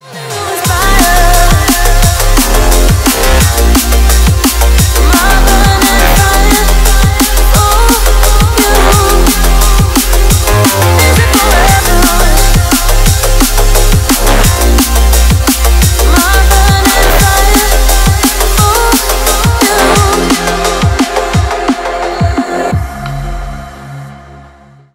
• Качество: 320, Stereo
громкие
мощные
женский голос
drum n bass
Стиль: drum & bass